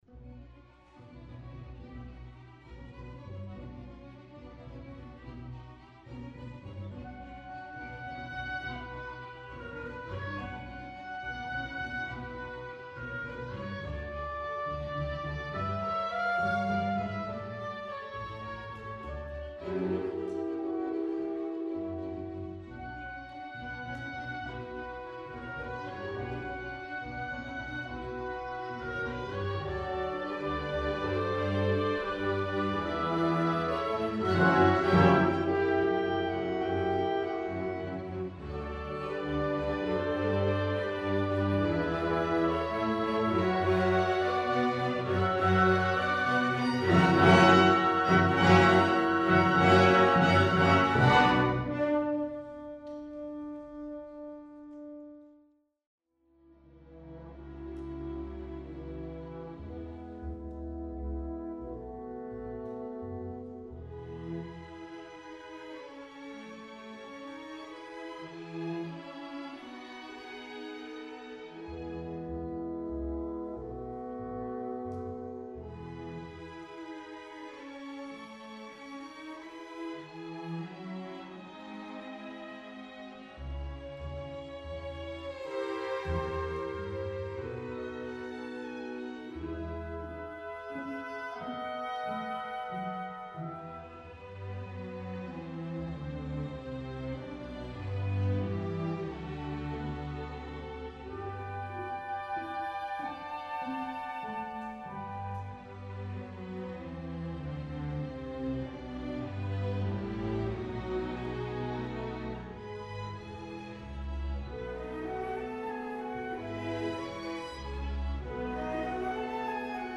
[GASP] = Great Audio Sneak Preview from rehearsal on April 16, 2007 - 4 more rehearsals... (:-)
I  Allegro moderato
II  Andante con moto
Unfinished_rehearsal_clip.mp3